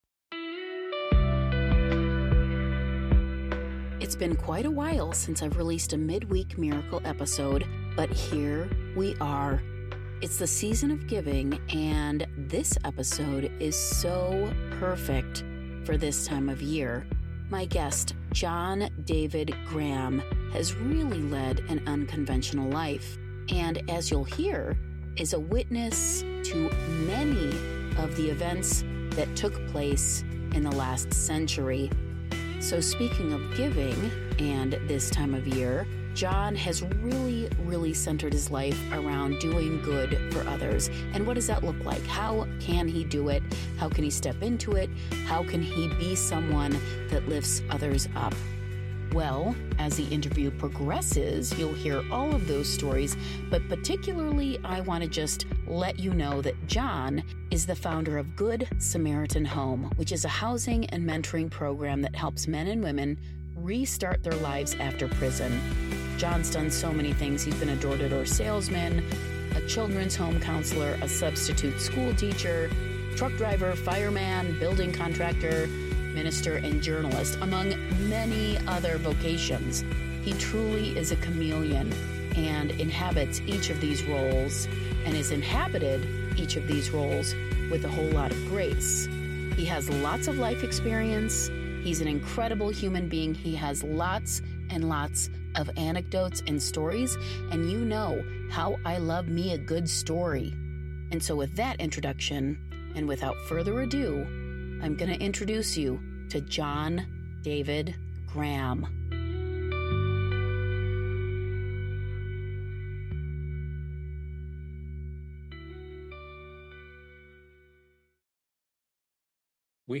He is bound and determined to leave this world better than he found it, and it is so apparent that he is called to be of service to his fellow human beings! What does an interviewer do with a guest who is a chameleon?